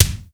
BIG BD 3.wav